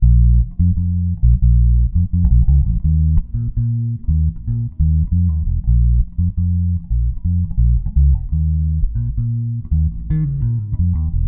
85Bpm拉丁低音
描述：为桑巴舞而生。和弦。降B调/降E调
Tag: 85 bpm Jazz Loops Bass Guitar Loops 1.90 MB wav Key : A